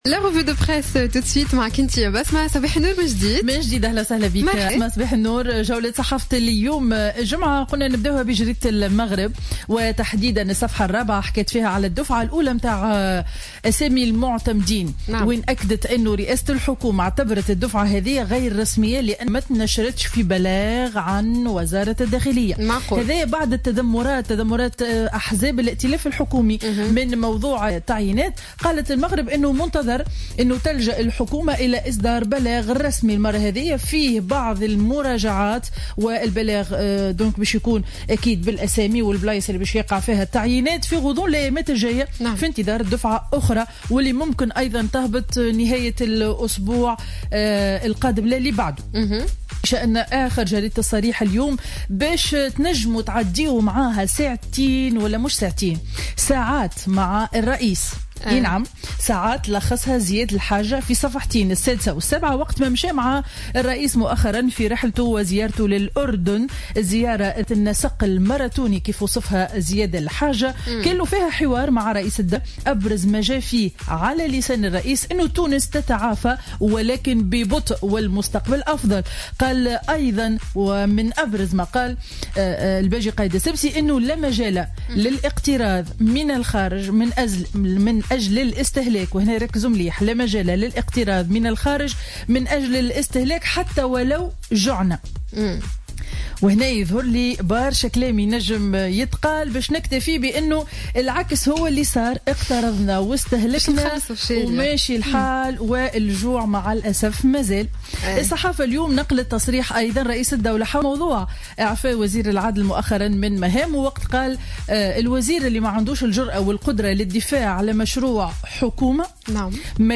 Revue de presse du vendredi 23 octobre 2015